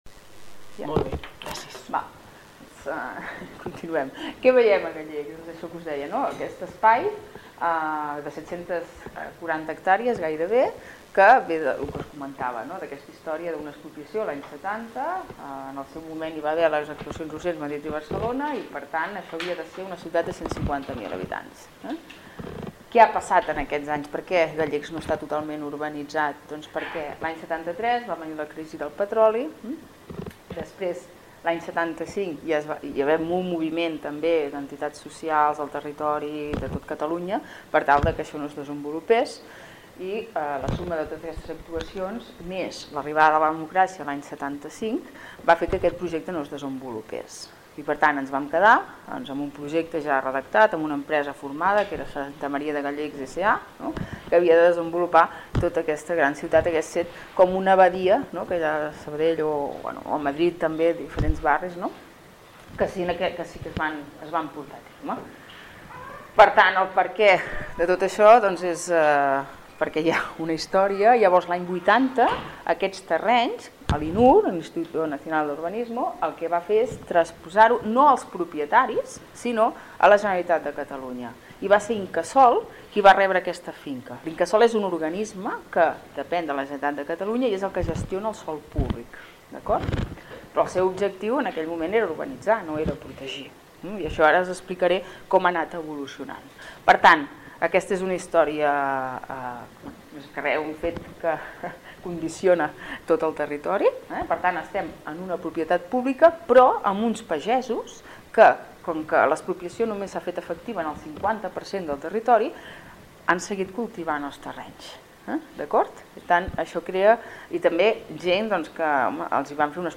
Xerrada-Consorci-de-Gallecs.mp3